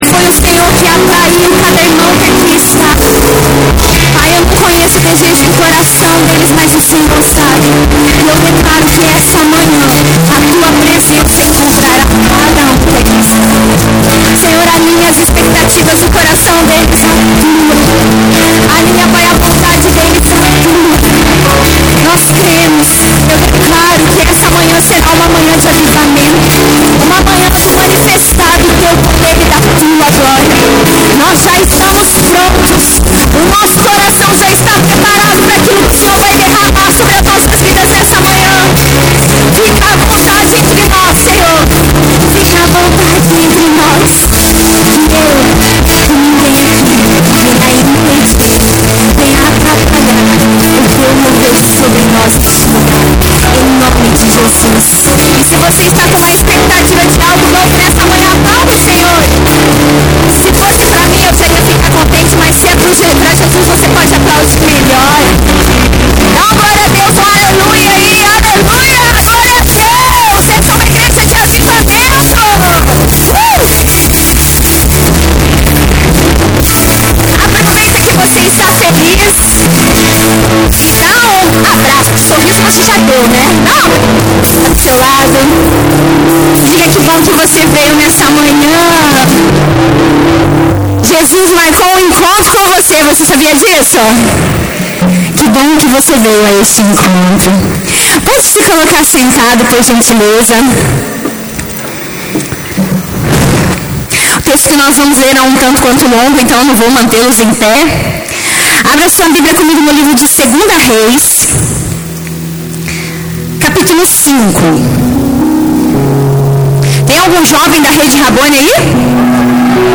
ICP - Igreja Cristã Presbiteriana